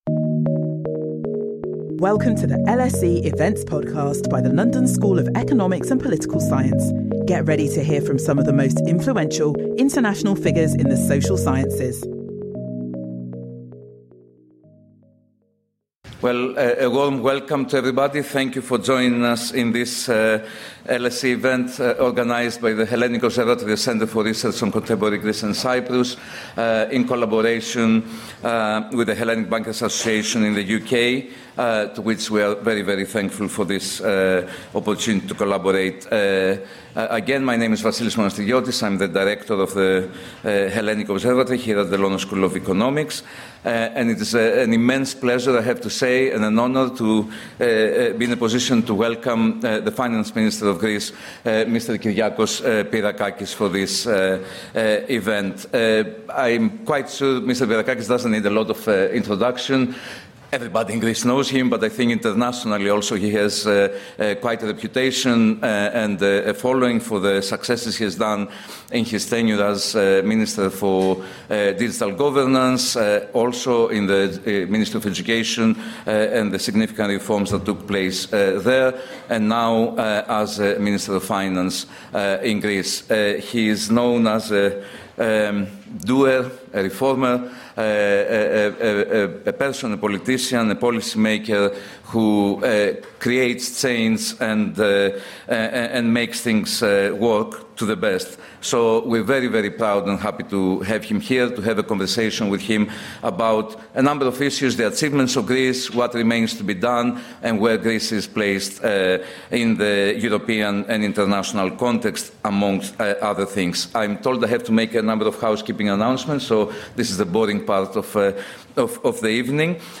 Join us for a discussion with Kyriakos Pierrakakis, Greece's Minister of the Economy and Finance, on the key challenges shaping the country’s future.